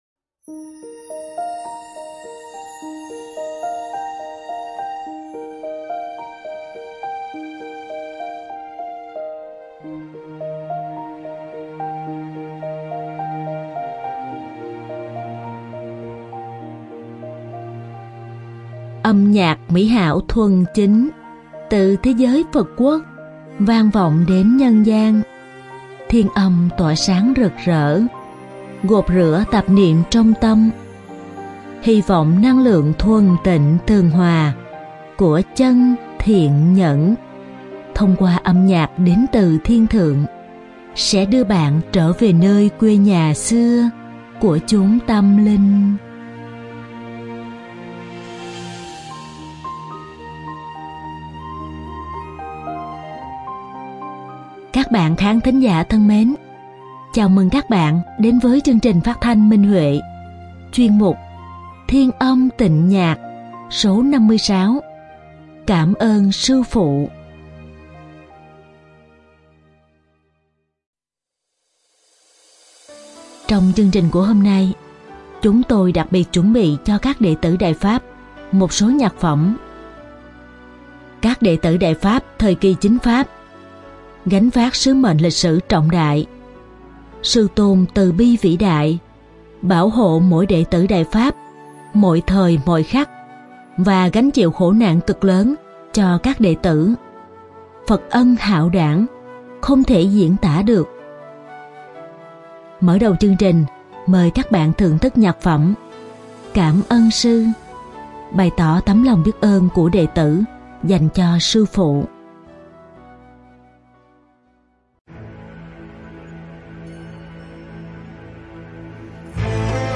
Âm nhạc mỹ hảo thuần chính